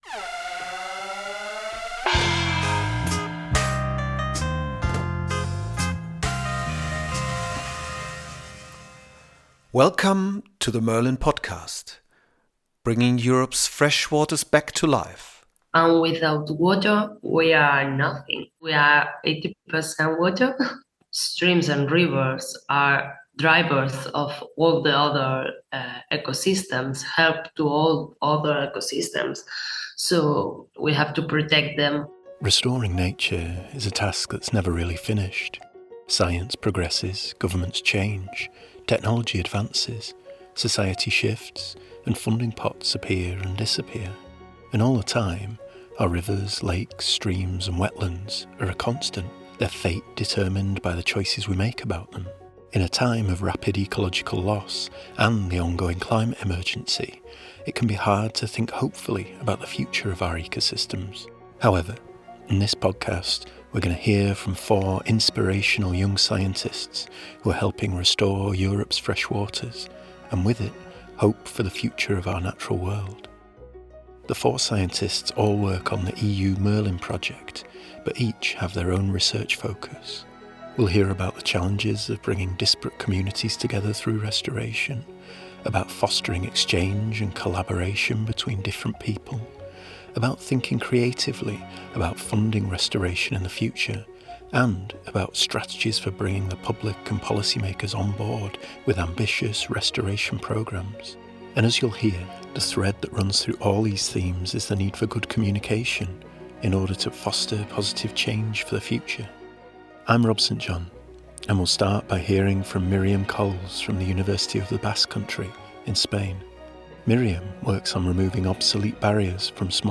In the new episode of the MERLIN podcast we hear from four inspirational young scientists who are helping restore Europe’s freshwaters, and with it, hope for the future of our natural world.